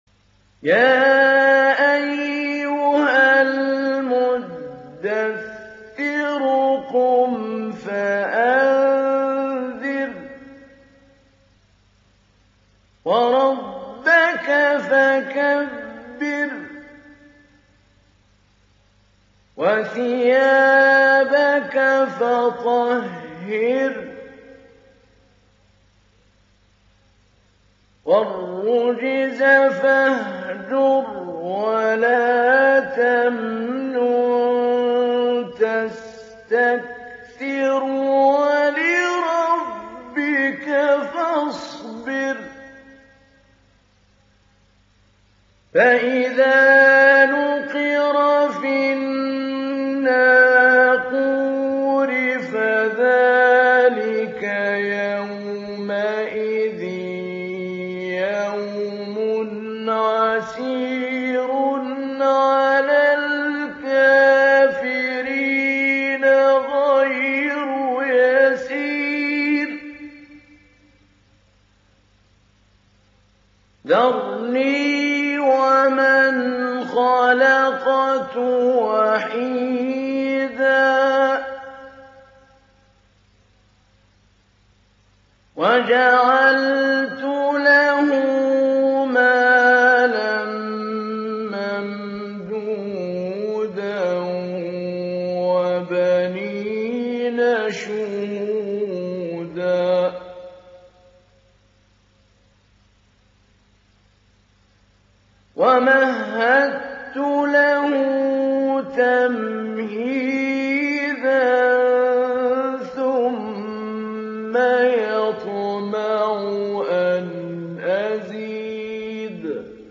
Download Surah Al Muddathir Mahmoud Ali Albanna Mujawwad